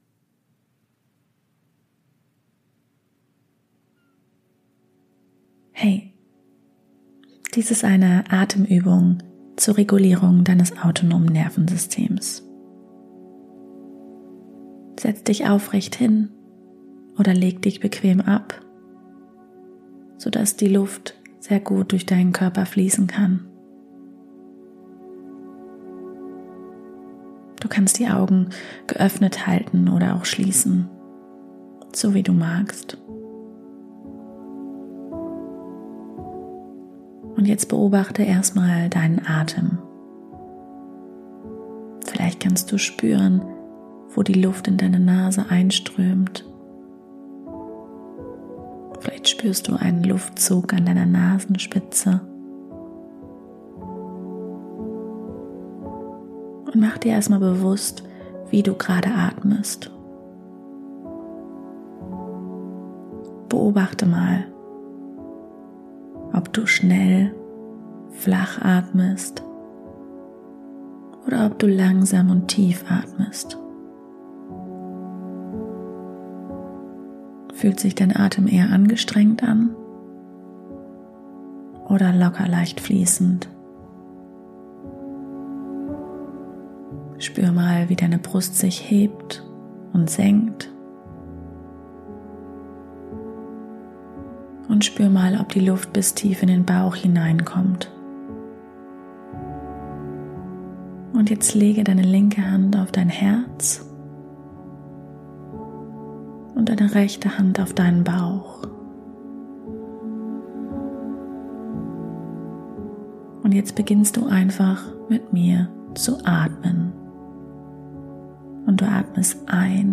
#26 Meditation: Atemanleitung um Ängste loszulassen